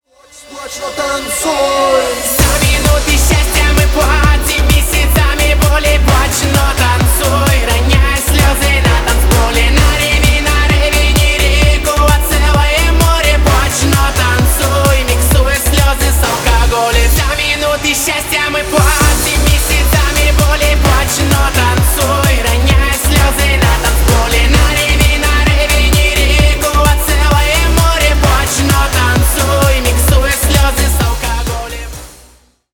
Поп Музыка # Танцевальные